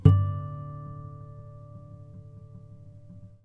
strings_harmonics
harmonic-06.wav